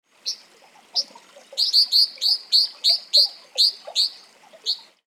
カワガラス｜日本の鳥百科｜サントリーの愛鳥活動
「日本の鳥百科」カワガラスの紹介です（鳴き声あり）。
kawagarasu_ch.mp3